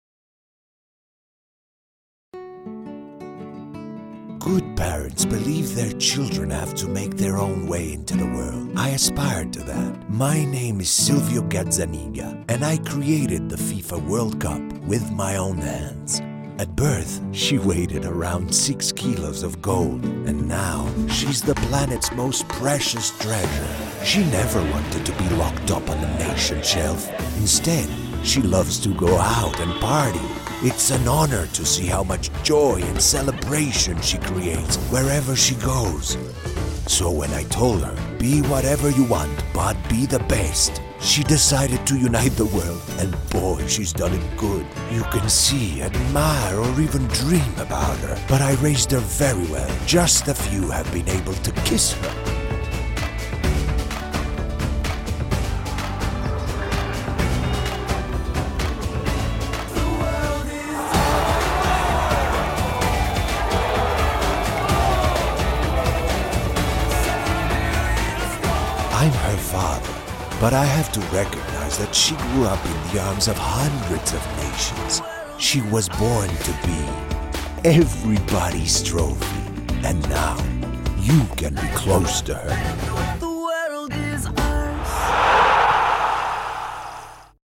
Deep and sensual bass baritone voice. Voix grave, profonde, sensuelle.
spanisch Südamerika
Sprechprobe: Sonstiges (Muttersprache):